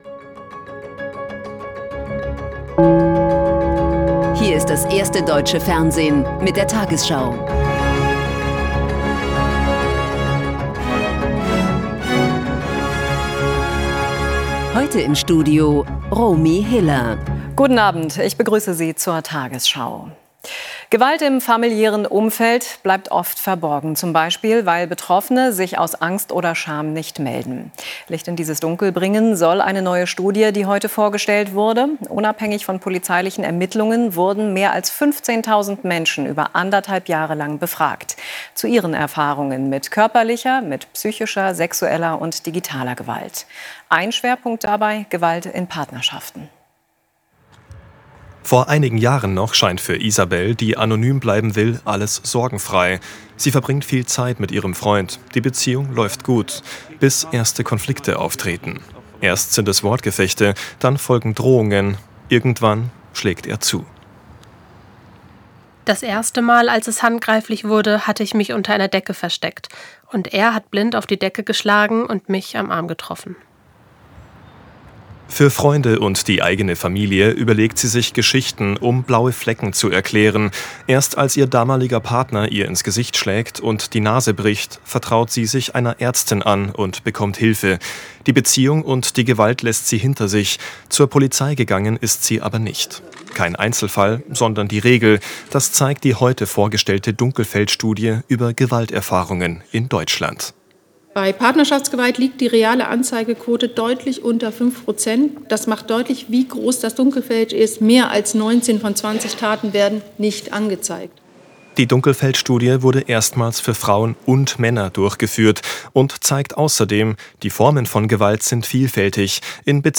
tagesschau 20:00 Uhr, 10.02.2026 ~ tagesschau: Die 20 Uhr Nachrichten (Audio) Podcast